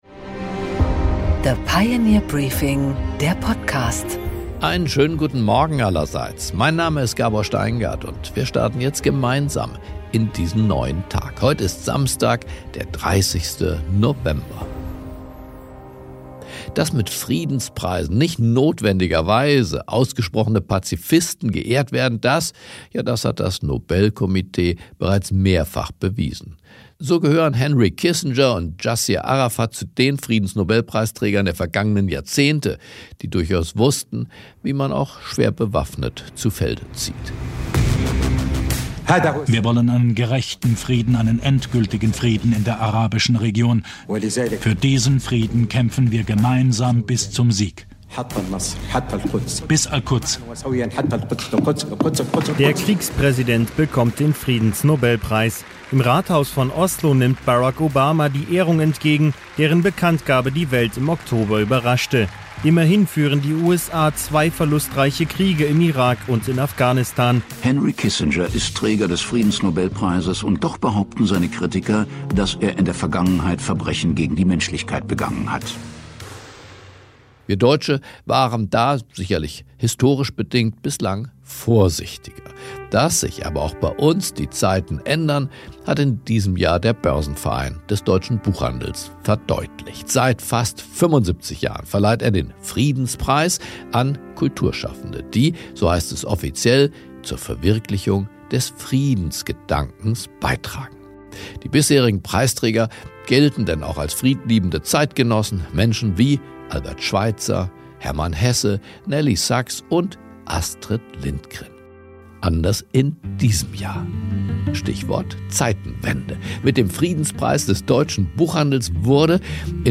Gabor Steingart im Gespräch mit Anne Applebaum über das Netzwerk und die Achse der Autokraten.
Im Gespräch mit Pioneer-Herausgeber Gabor Steingart spricht die US-Amerikanerin über die Gefahren, die von den führenden Köpfen dieser Länder ausgehen: